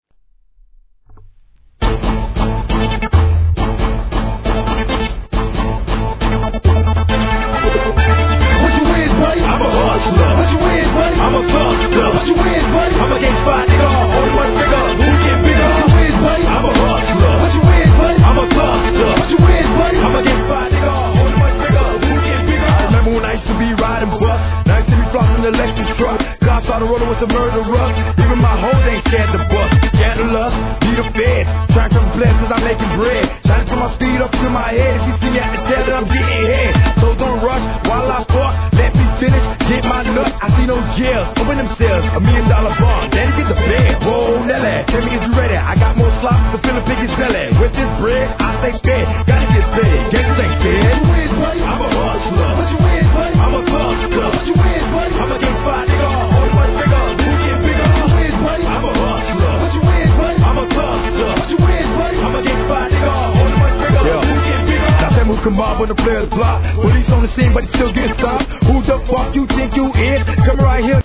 G-RAP/WEST COAST/SOUTH
シンセの引き乱れるド派手なトラックで、モンスター級RAPを披露!!!